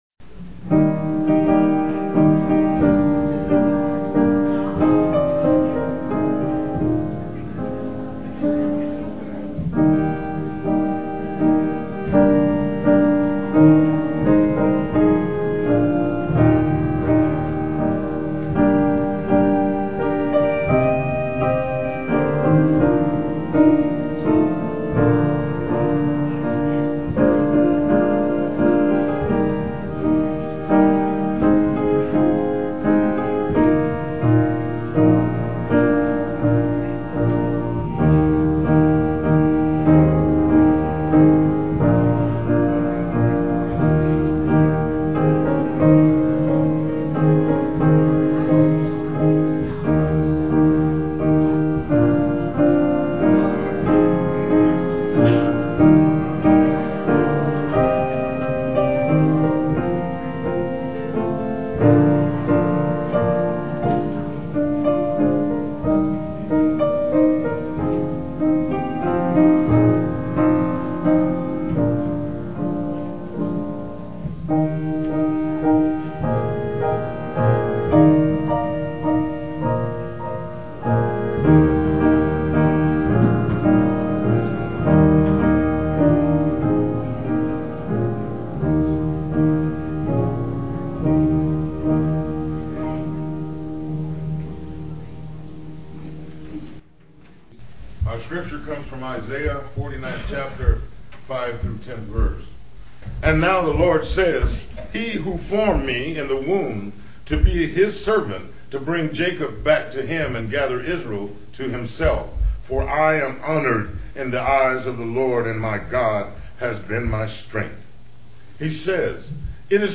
PLAY That All May Know Him, Dec 3, 2006 Scripture: Isaiah 49:5-10. Scripture Reading